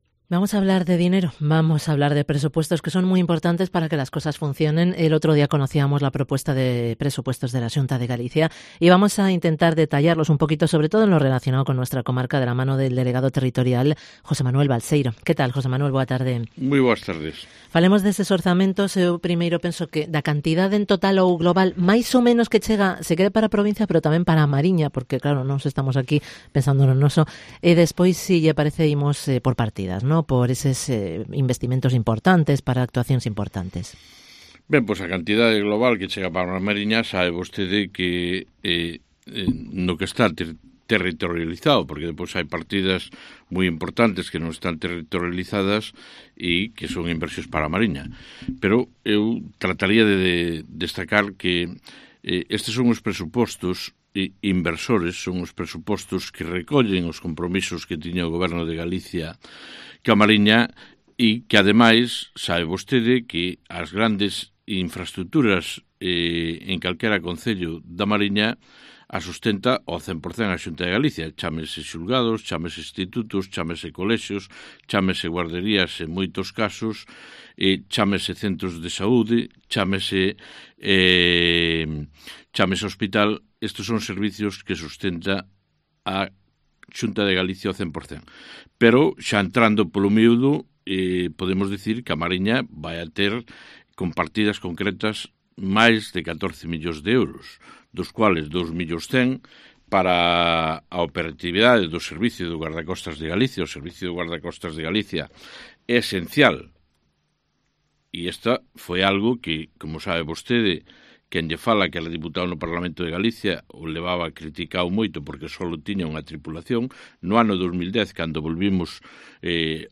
ENTREVISTA con el delegado territorial de la Xunta, José Manuel Balseiro